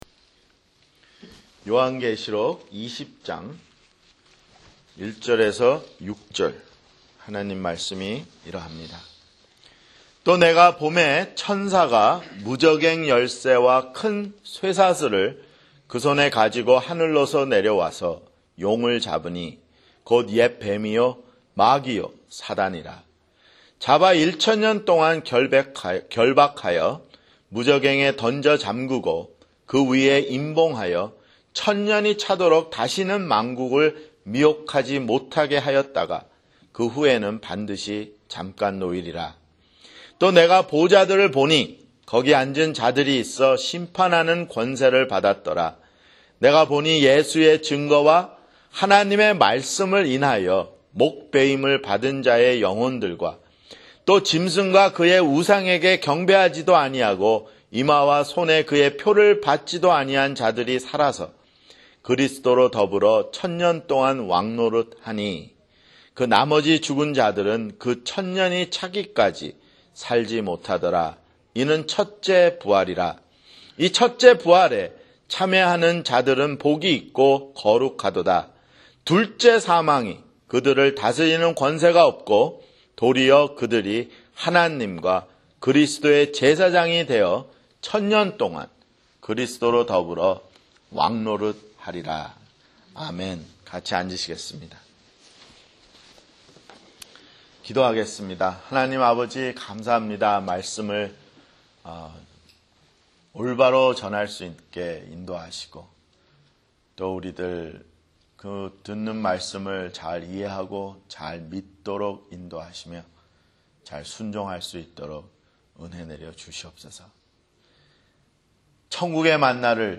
[주일설교] 요한계시록 (76)